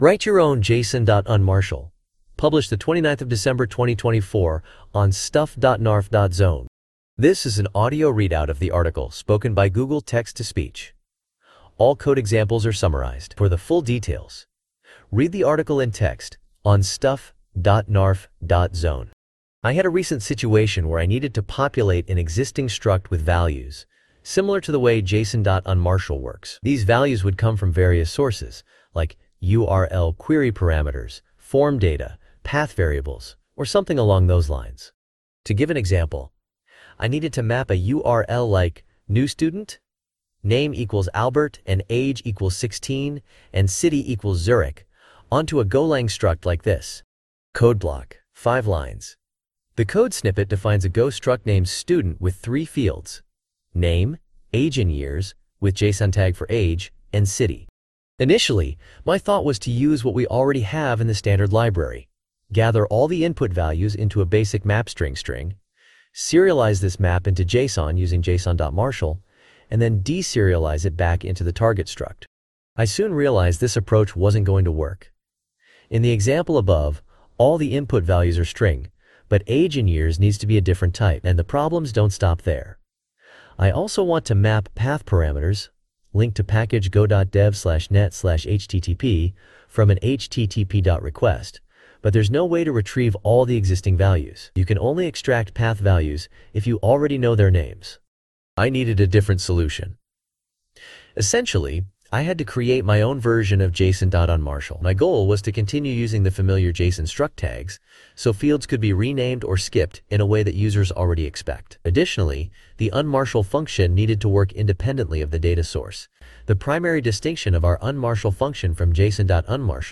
Posted on 2024-12-29 :: 24 min read :: Tags: golang :: listen via tts I had a recent situation where I needed to populate an existing struct with values, similar to the way json.Unmarshal works.
unmarshal-tts.mp3